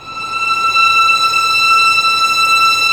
Index of /90_sSampleCDs/Roland LCDP13 String Sections/STR_Violins II/STR_Vls6 mf amb